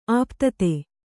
♪ āptate